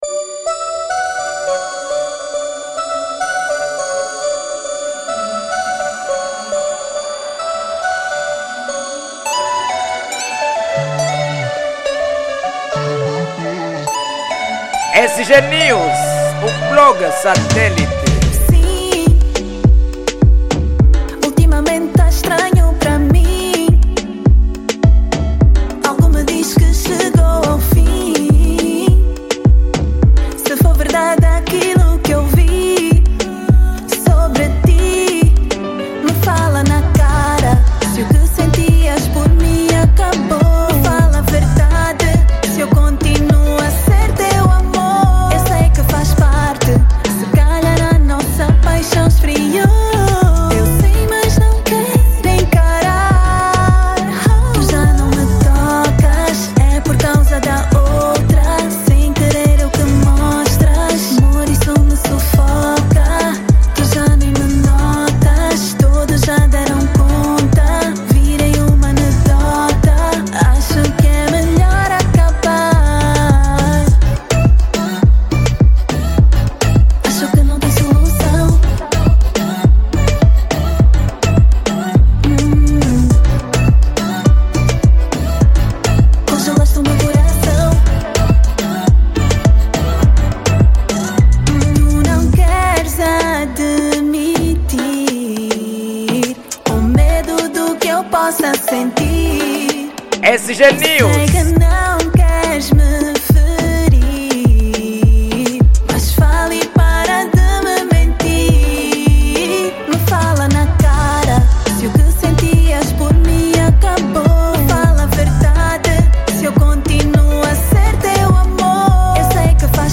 Género : Reggaeton